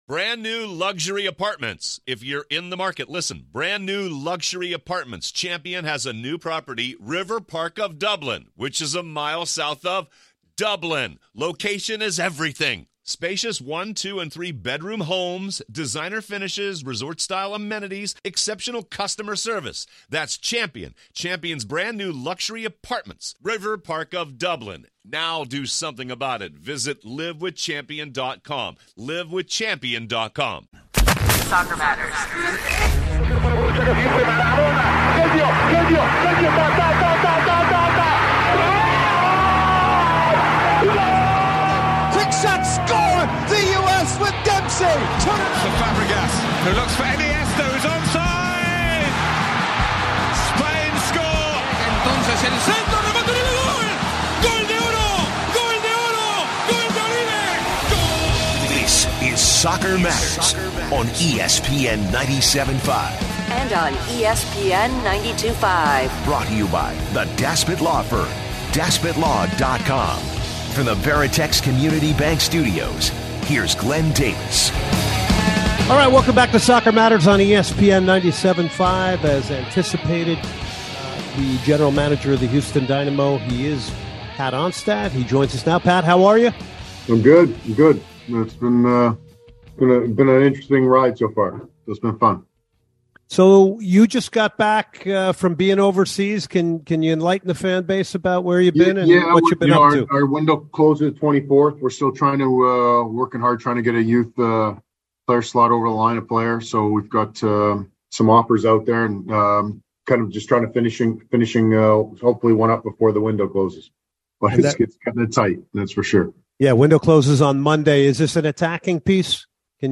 two interviews